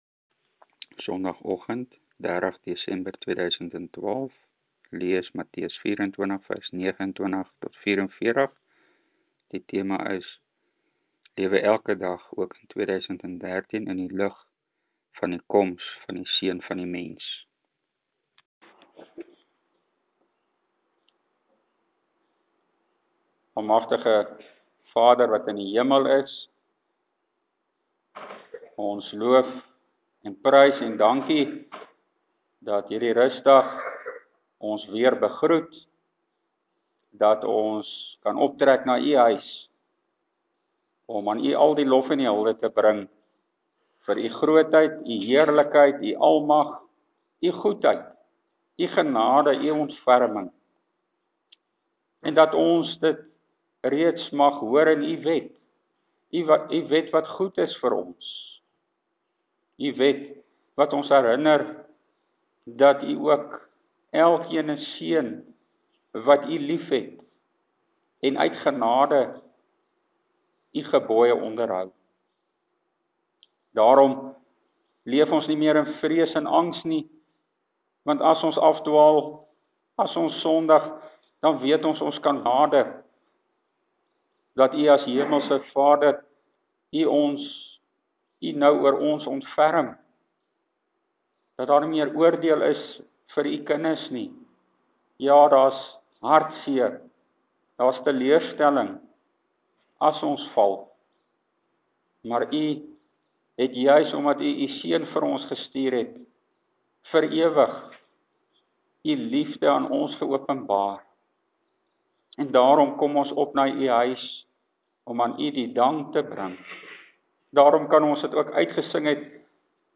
Hier is my ‘nuwejaarspreek’ wat ek afgelope Sondag gelewer het: Ons moet elke dag lewe in die lig van Christus se koms, ook in 2013 Teksverse: Matt.24:36-46.